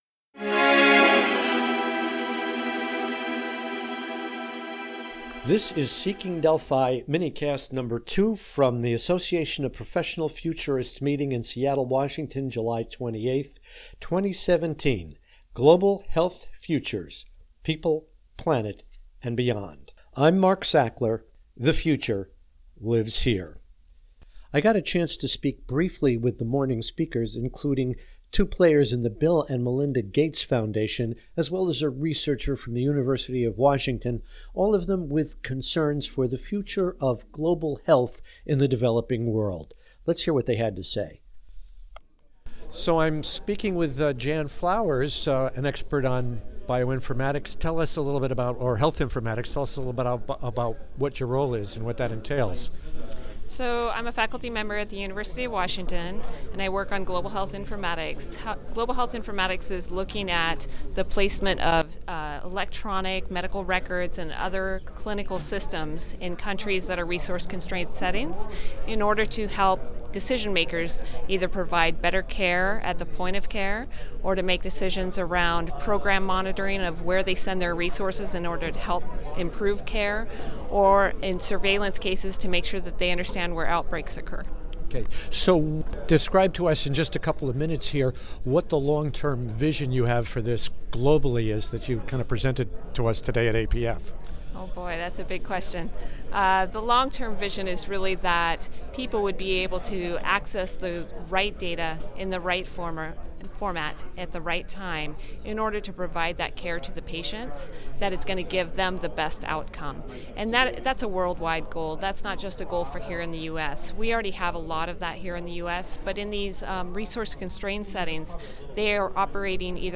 Today’s sessions at the Association of Professional Futurist’s annual meeting in Seattle, Washington, consisted of morning sessions on efforts to improve human health in the third world.
They provide brief summaries of their work in today’s mini-cast.